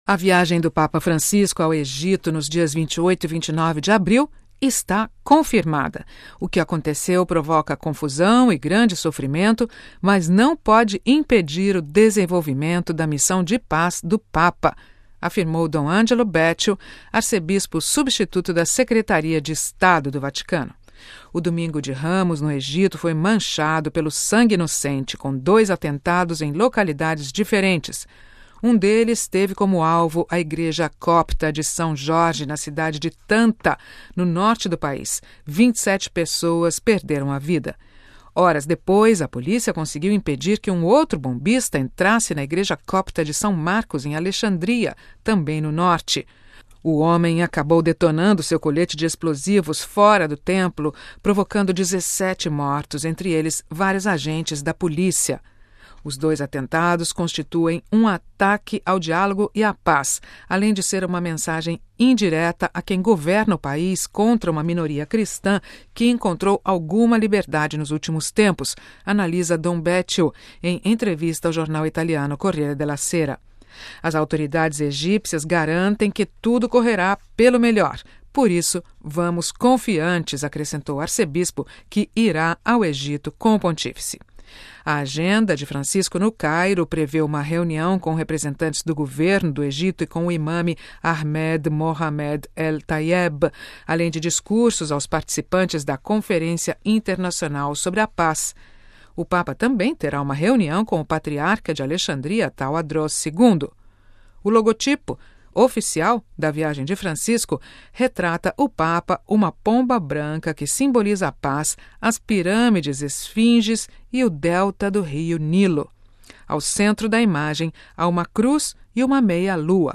Depois de ter conhecimento dos atentados, o Papa expressou sua dor durante o encontro com os fiéis na Praça São Pedro, para a oração do Angelus.